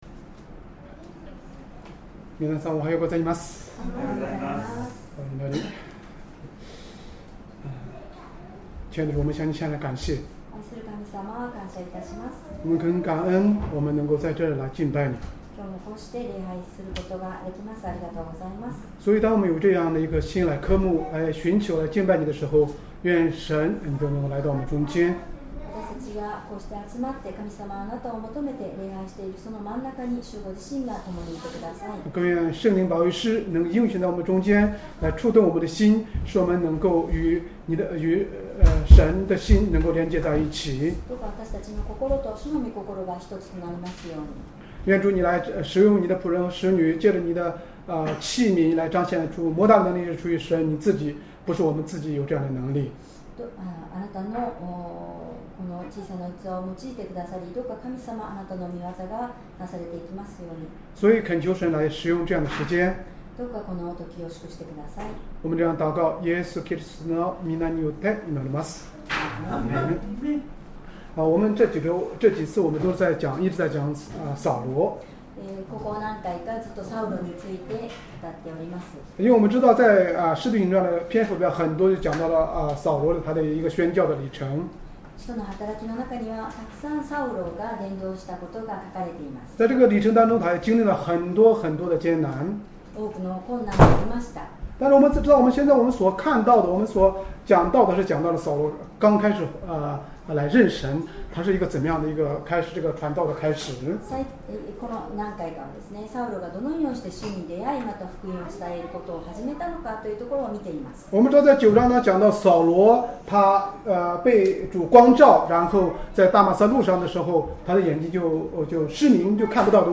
Sermon
2025年9月14日 主日礼拝 説教 「サウロが伝道する中で直面した出来事」 聖書 使徒の働き9章26-31節 9:26 エルサレムに着いて、サウロは弟子たちの仲間に入ろうと試みたが、みな、彼が弟子であるとは信じず、彼を恐れていた。